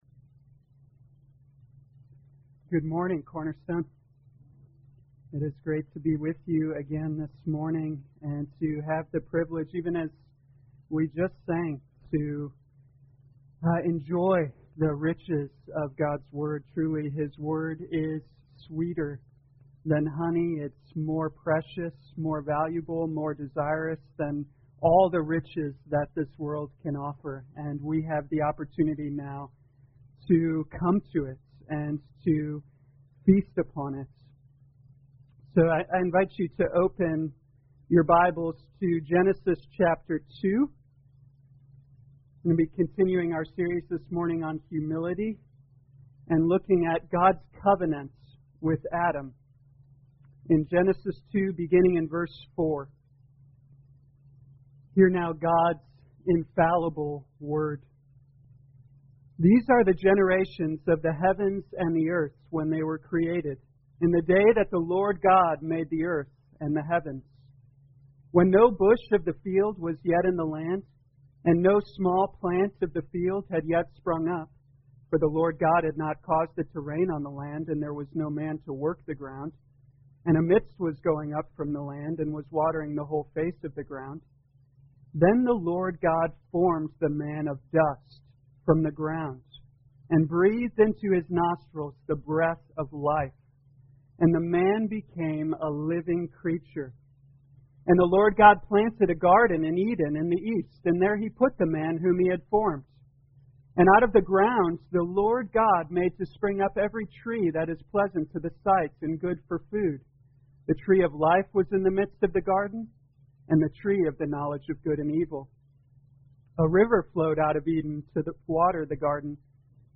2020 Genesis Humility Morning Service Download